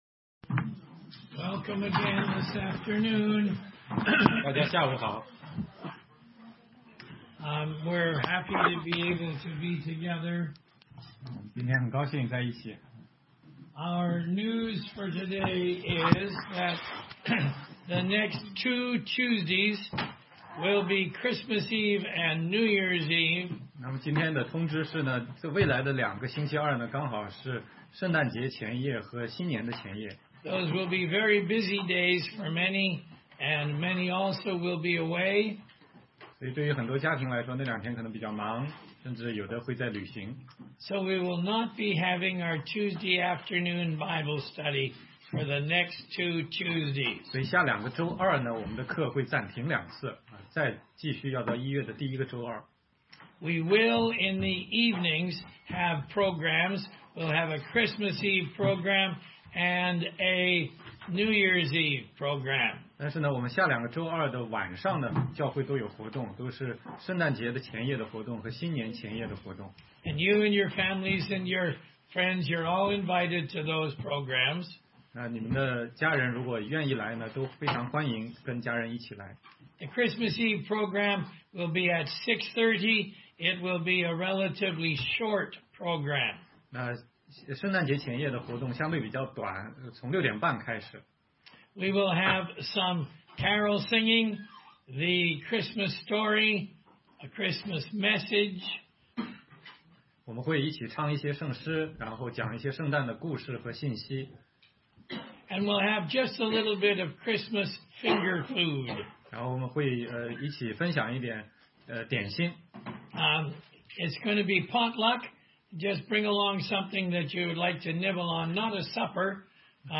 16街讲道录音 - 哥林多前书11章1-16节：为什么教会聚会时男人要脱帽，女人要蒙头？